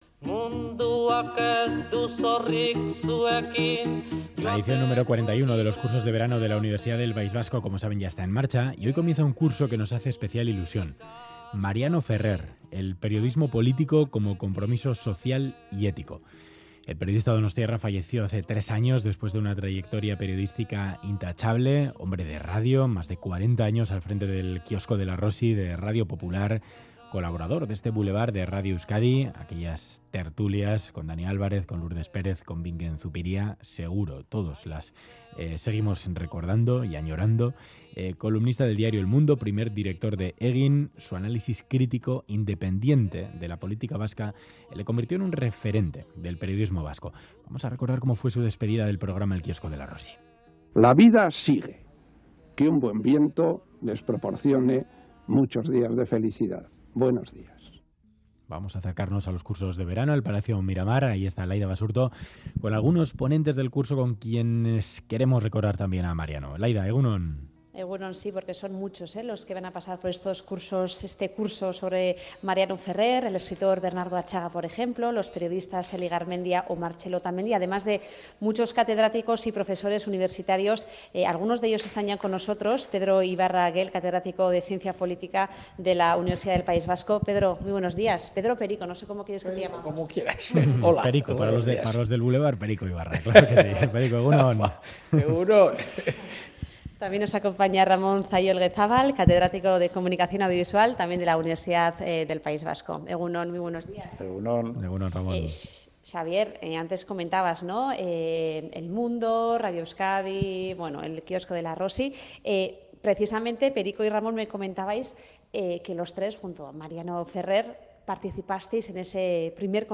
Audio: Curso de verano UPV, horarios y contenidos y entrevistas a profesores